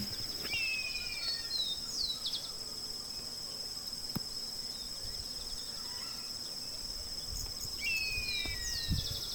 Savanna Hawk (Buteogallus meridionalis)
Location or protected area: Ceibas
Condition: Wild
Certainty: Observed, Recorded vocal